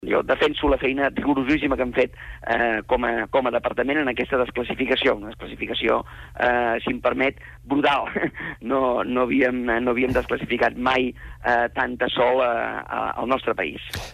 Davant d’això, Calvet s’ha preguntat en declaracions a SER Catalunya com pot ser que no estiguin satisfets amb el pas endavant que suposa el PDU.